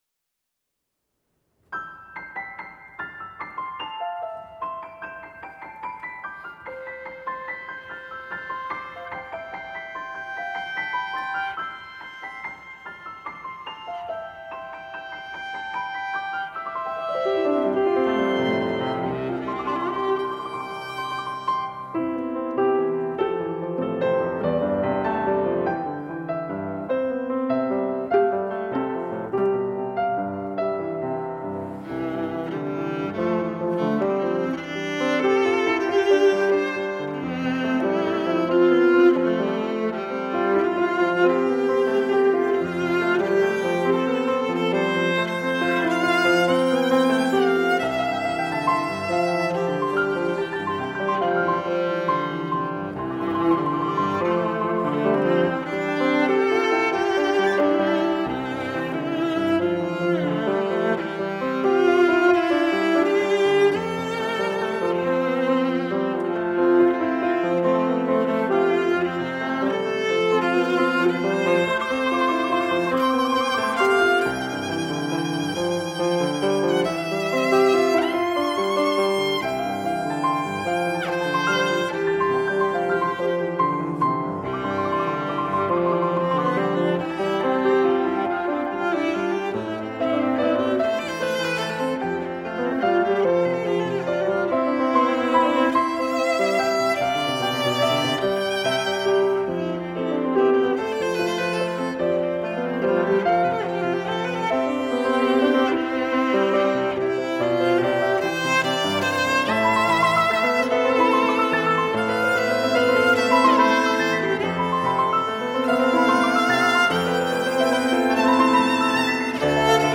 ·        Mood: cinematic, lyrical
Piano + Viola